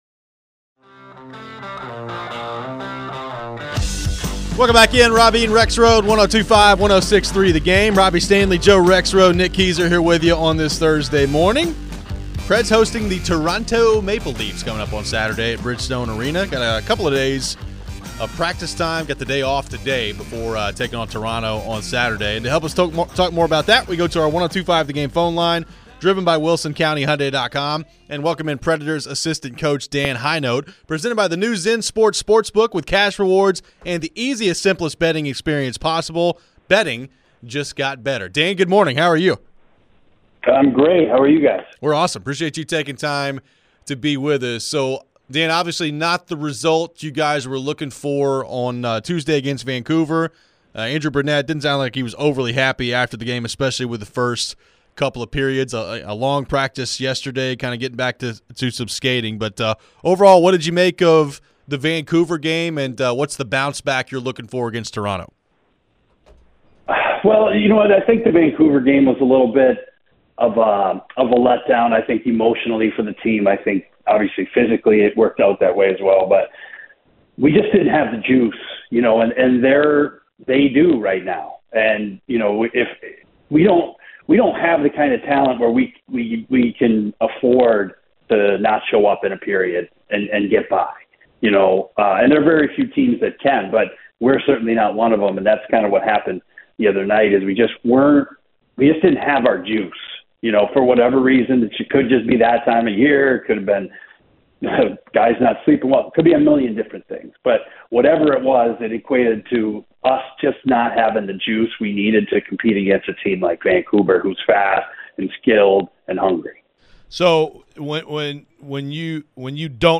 Dan Hinote Interview (10-26-23)